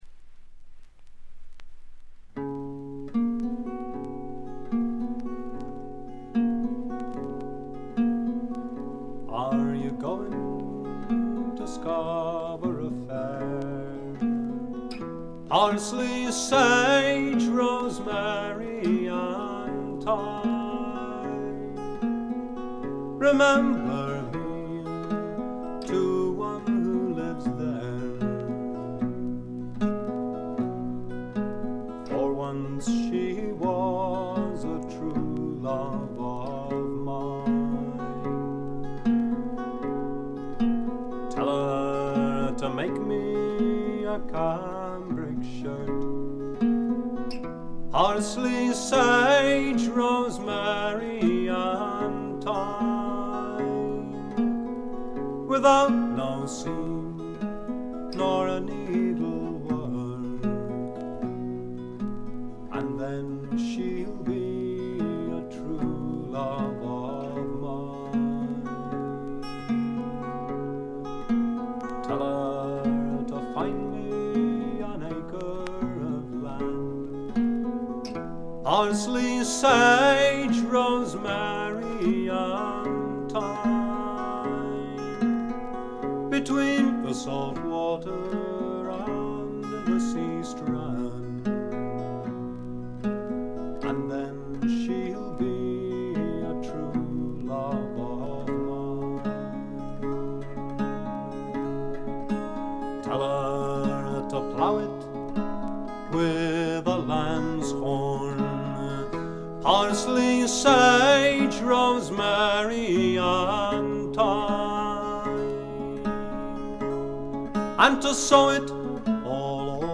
ところどころで軽微なチリプチ。目立つノイズはありません。
英国フォーク必聴盤。
Stereo盤。
試聴曲は現品からの取り込み音源です。